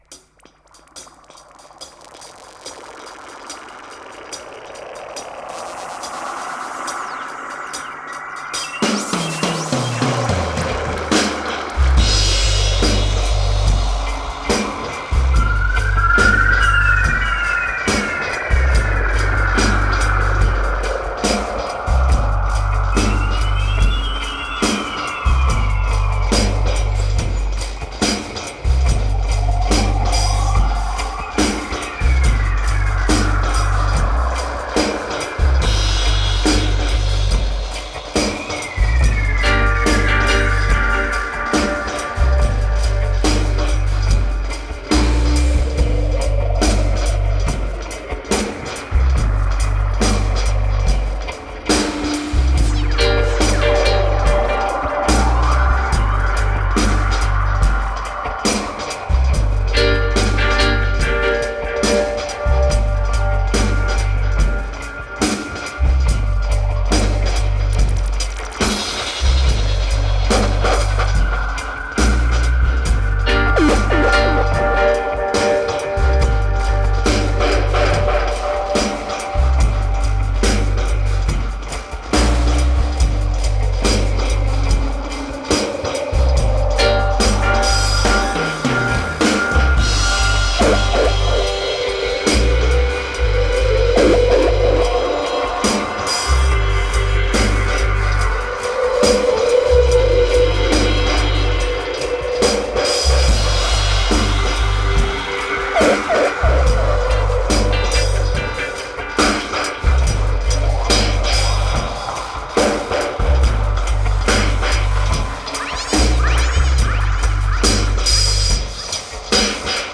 Genre: Dub, Downtempo, Ambient.